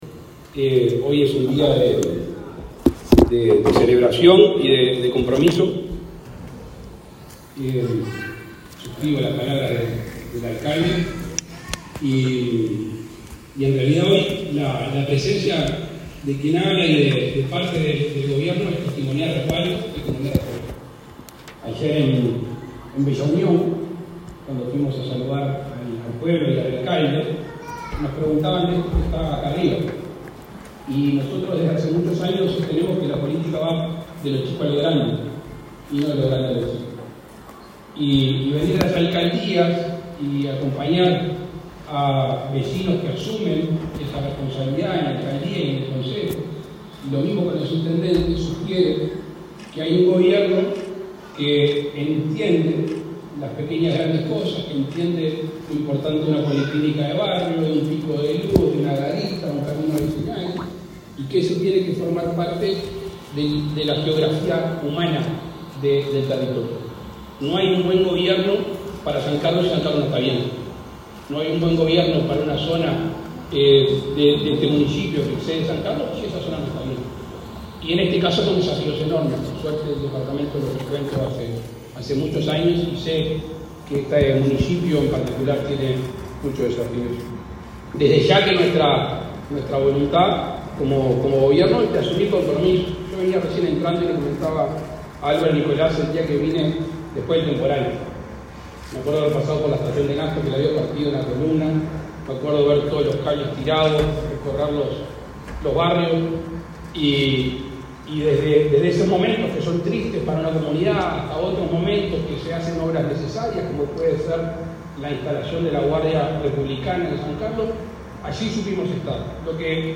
Oratoria del presidente Lacalle Pou en asunción del alcalde de San Carlos
El presidente de la República, Luis Lacalle Pou, participó este viernes 27 en la ceremonia de asunción del alcalde de San Carlos, Carlos Pereyra, en